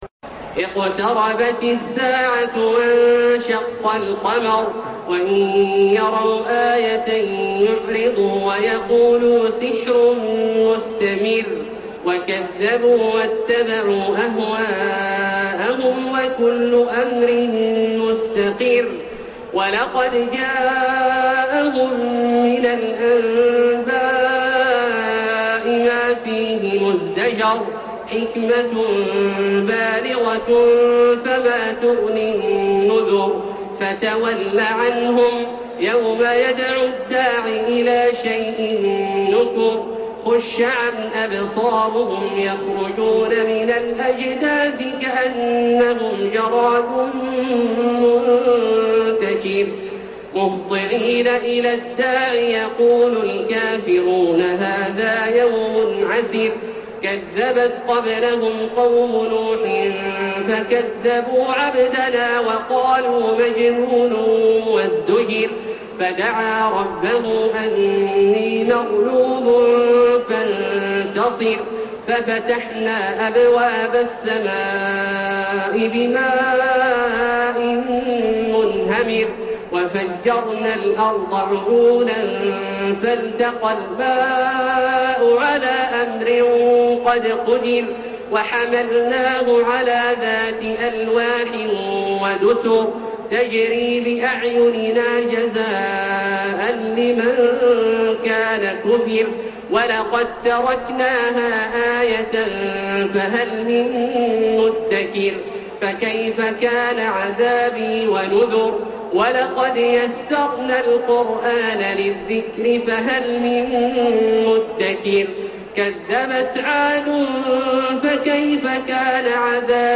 تحميل سورة القمر بصوت تراويح الحرم المكي 1432
مرتل